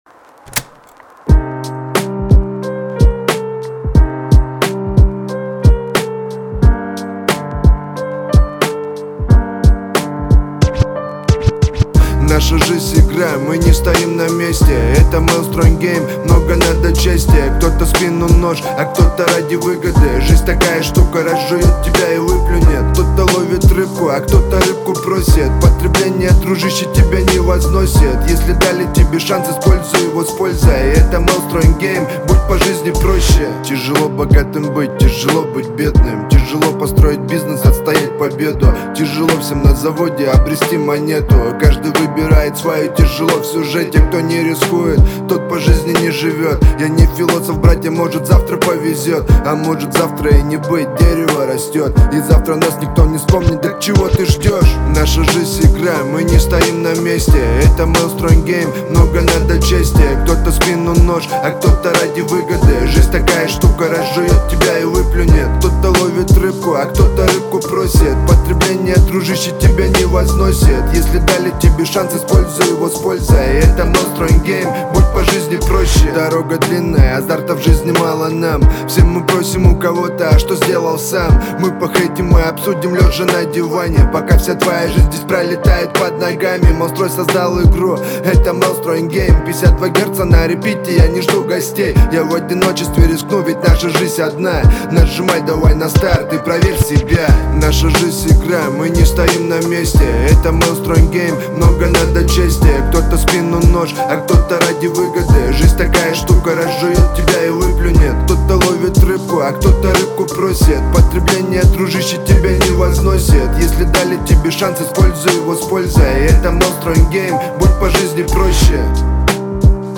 Качество: 320 kbps, stereo
Современная музыка